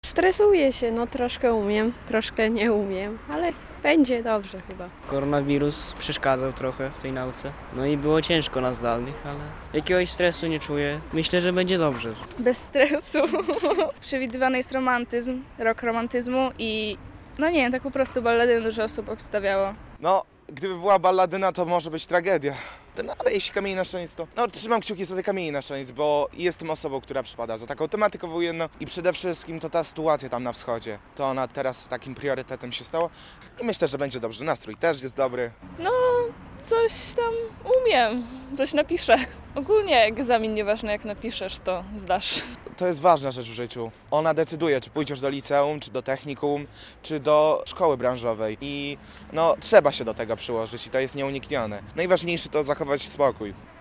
Ponad 14 tys. uczniów ósmych klas szkół podstawowych z regionu przystąpiło do obowiązkowego egzaminu na zakończenie szkoły - relacja